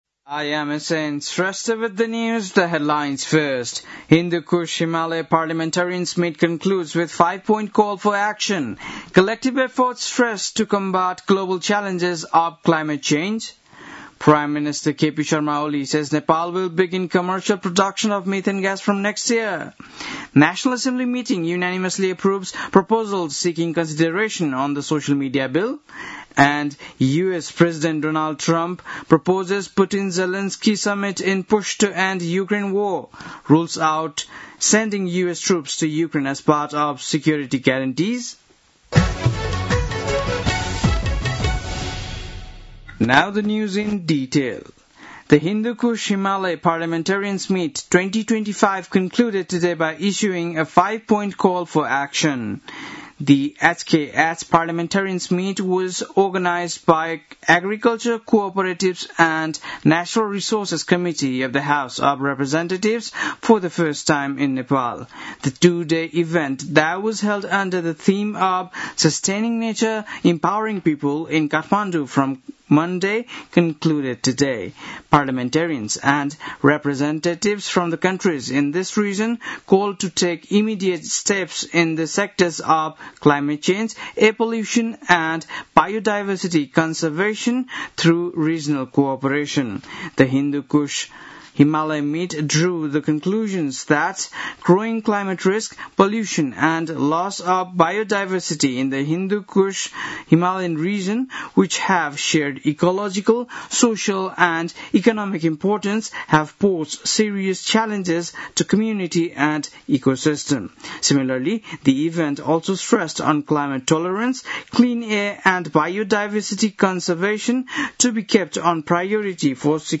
बेलुकी ८ बजेको अङ्ग्रेजी समाचार : ३ भदौ , २०८२